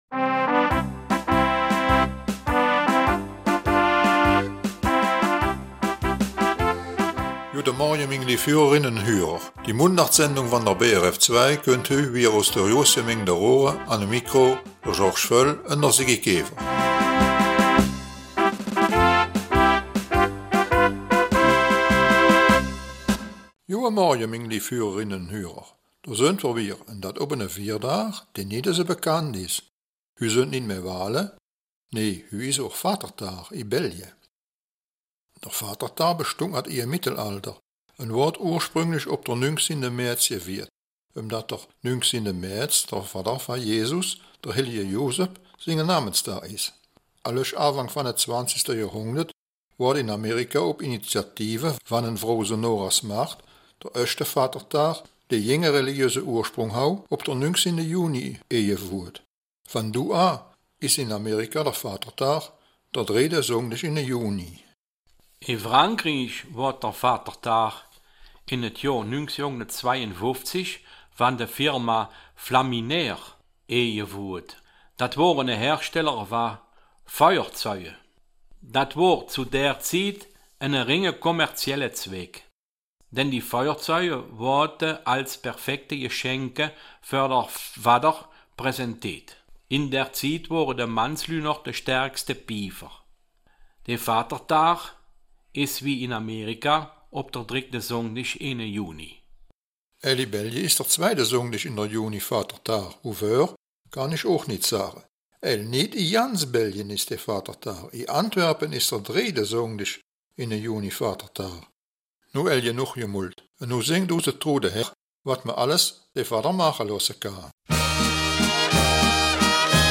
Raerener Mundart: Vatertag